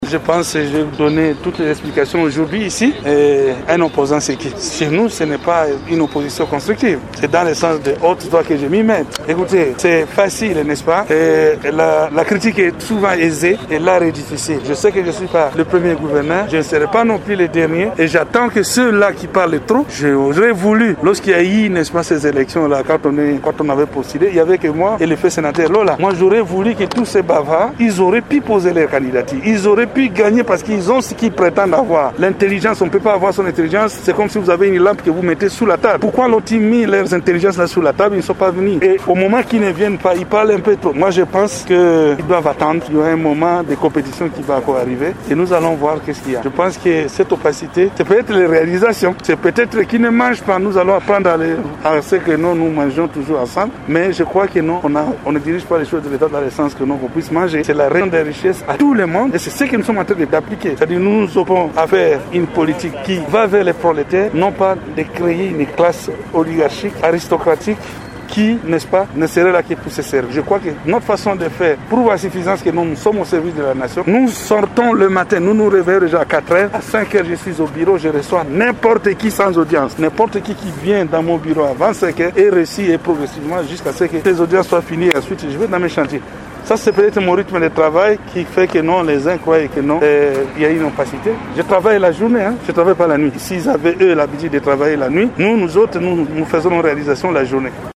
C’était lors d’une réunion tenue au terme de sa mission dans la contrée à l’intention des forces vives de Watsa dans la salle polyvalente de Durba pour expliquer sa vision de gouvernance et lever l’équivoque sur plusieurs questions et particulièrement ce sujet à forte polémique.
gouverneur-baseane-nangaa-sur-le-fonds-de-la-redevance-miniere.mp3